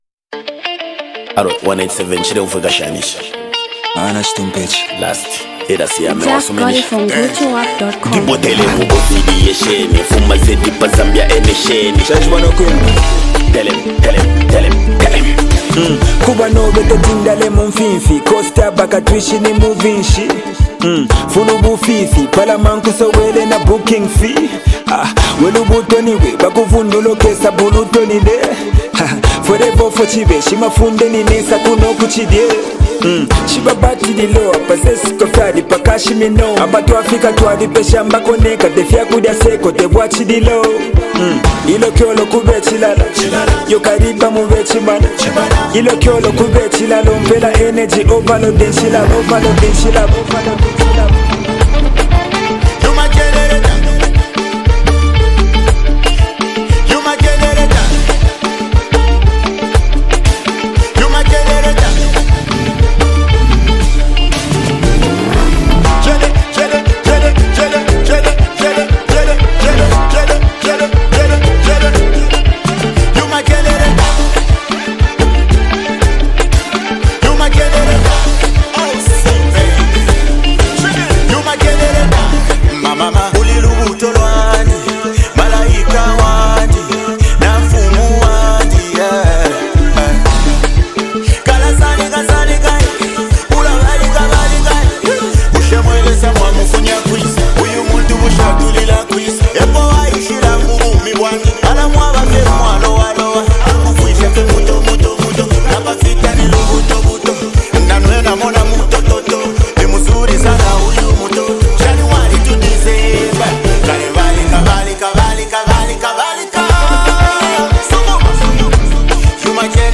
Zambian Mp3 Music